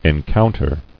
[en·coun·ter]